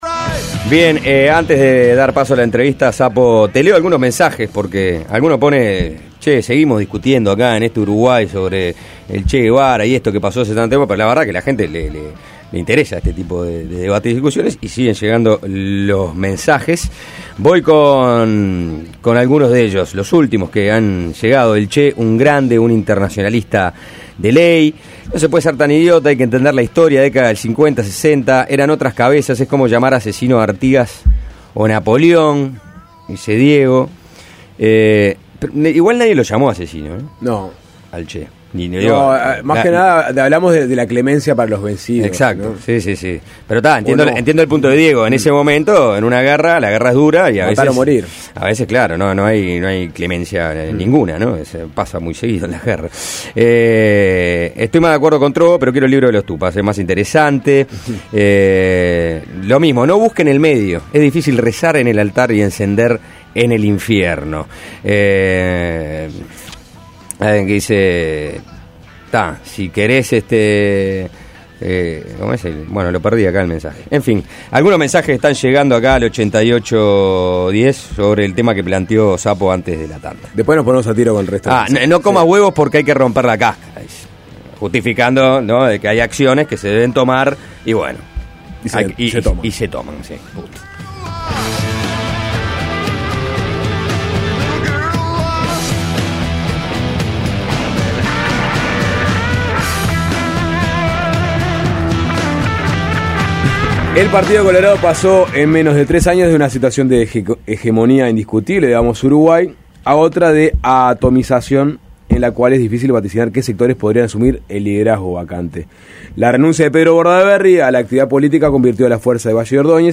En entrevista con Suena Tremendo